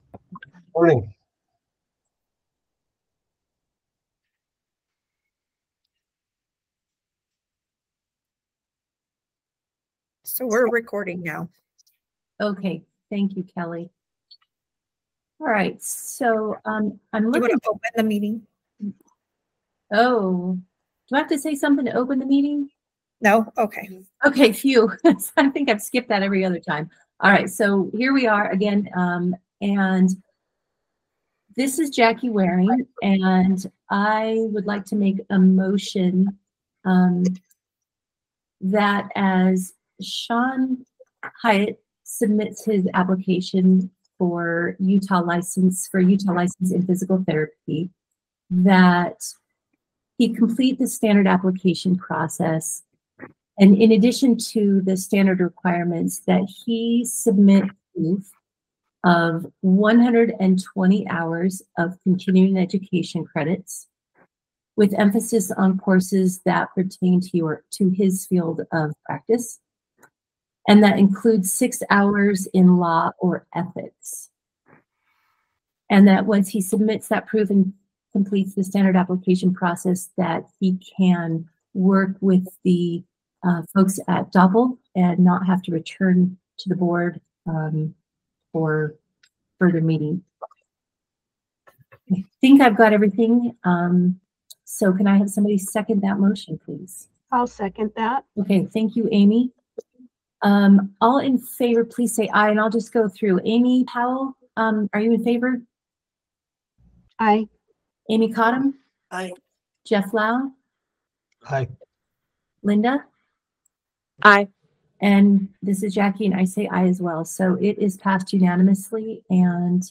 Meeting
Electronic participation is planned for this meeting.